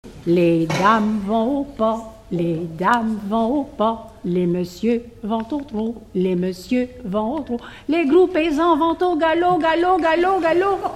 formulette enfantine : sauteuse
Pièce musicale inédite